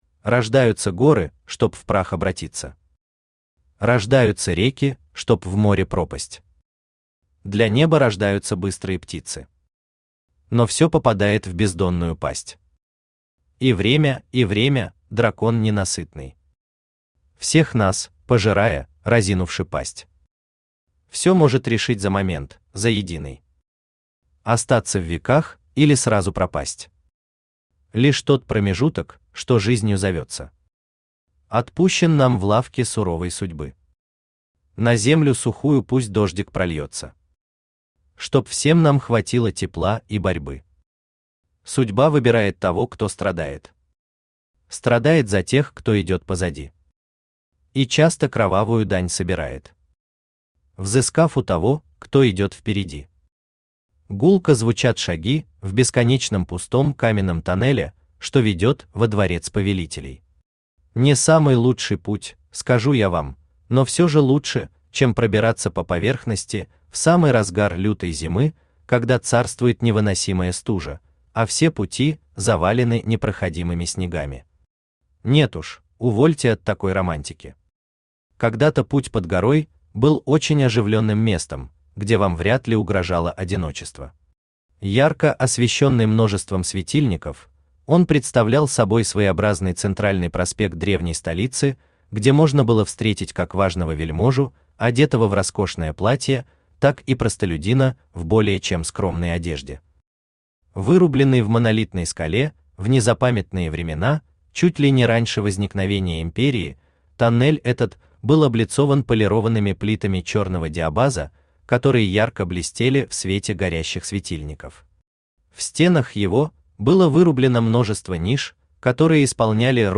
Аудиокнига Последний дракон. Книга 1. Неисповедимы пути драконов | Библиотека аудиокниг
Aудиокнига Последний дракон. Книга 1. Неисповедимы пути драконов Автор Всеволод Всеволодович Протопопов Читает аудиокнигу Авточтец ЛитРес.